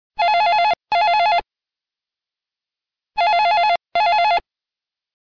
telephone rings
Warbleshort.mp3